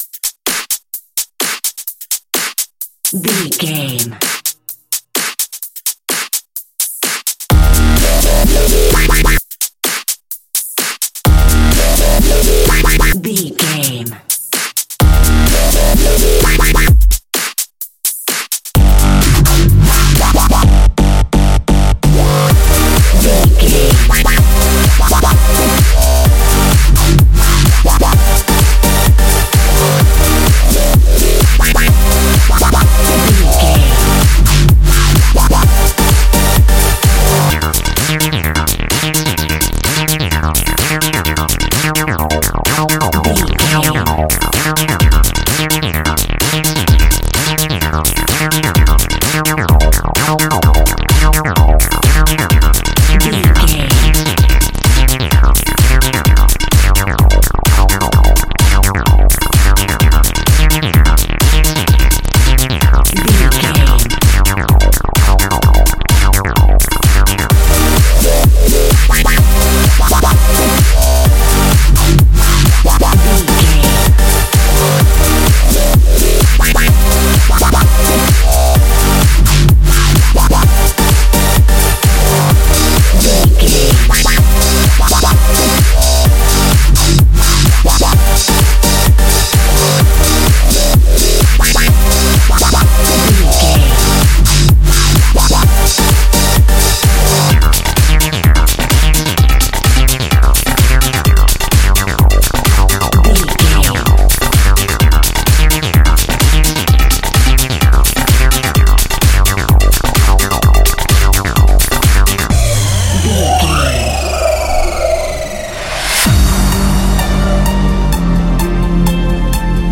Fast paced
Aeolian/Minor
aggressive
powerful
dark
driving
energetic
intense
drum machine
synthesiser
breakbeat
synth leads
synth bass